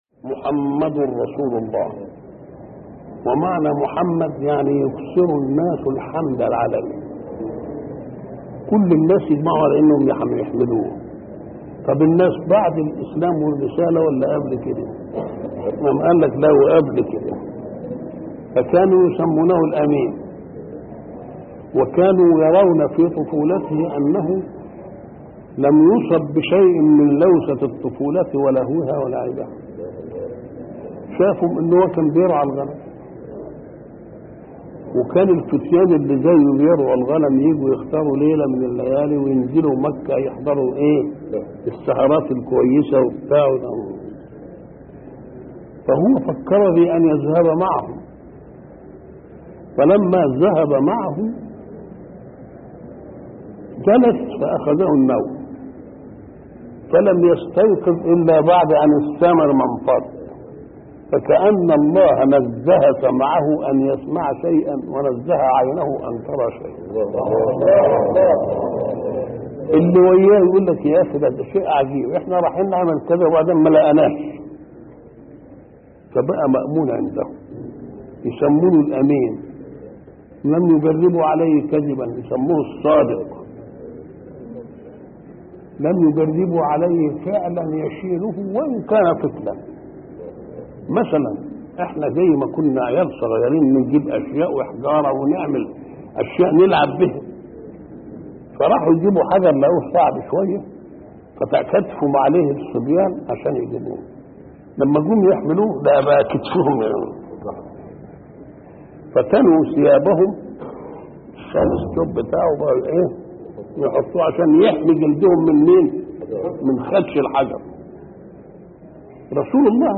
شبكة المعرفة الإسلامية | الدروس | النبي طفولة وشبابا |محمد متولي الشعراوي